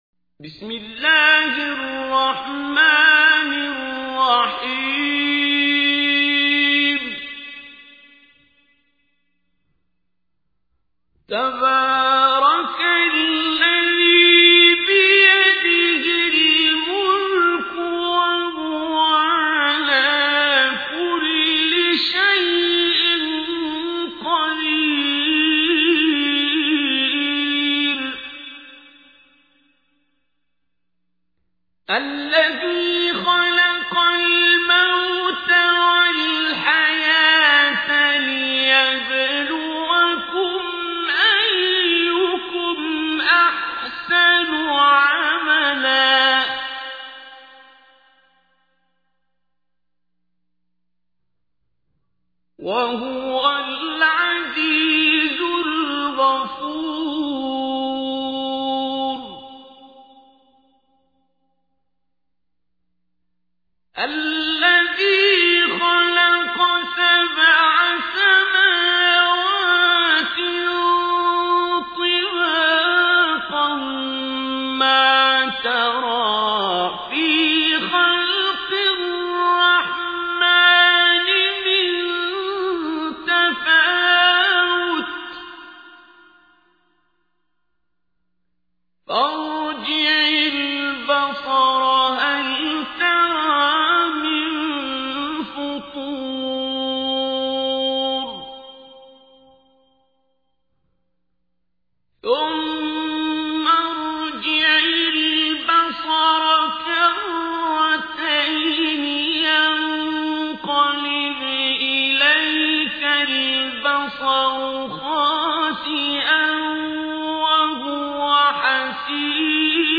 تحميل : 67. سورة الملك / القارئ عبد الباسط عبد الصمد / القرآن الكريم / موقع يا حسين